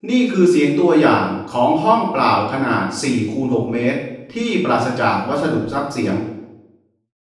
BEFORE ACOUSTIC TREATMENT
by Planet Green | 4m x6m EMPTY ROOM
Convoled_4_6_untreated_room.wav